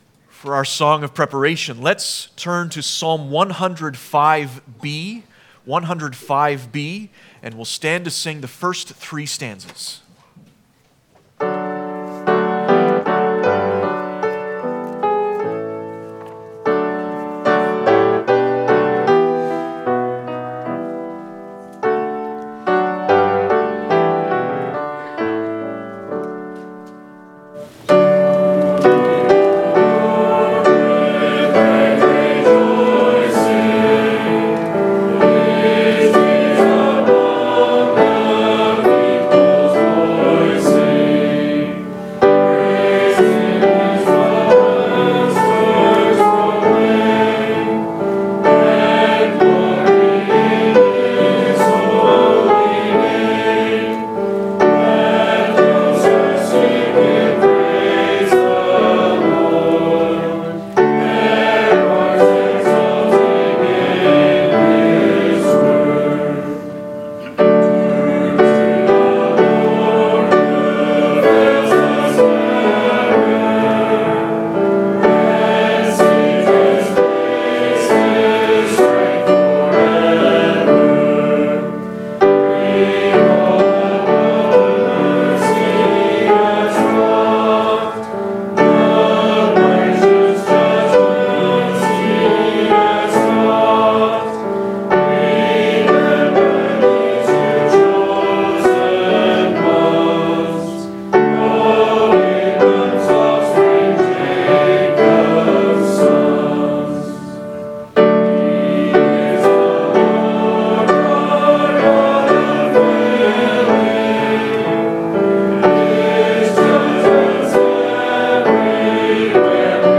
Service Type: Thanksgiving